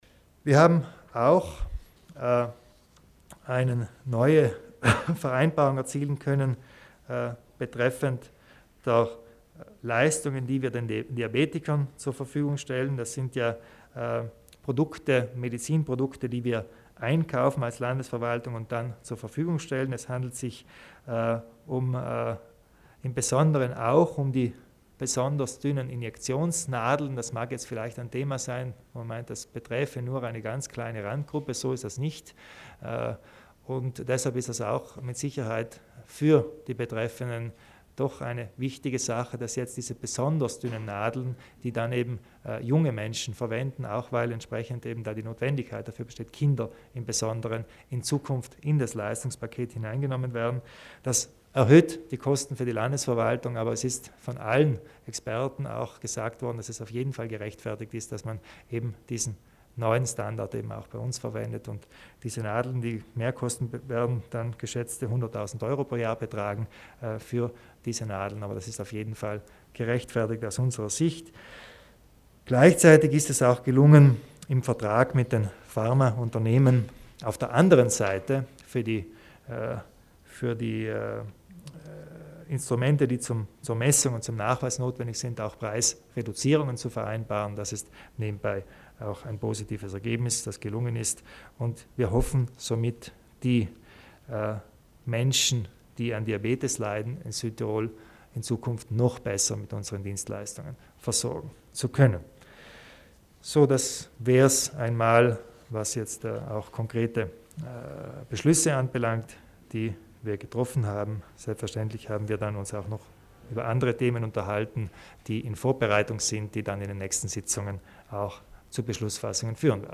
Landeshauptmann Kompatscher über die Neuheiten im Gesundheitsbereich